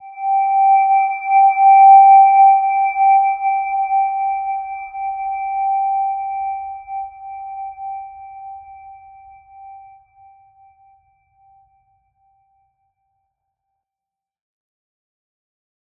Silver-Gem-G5-p.wav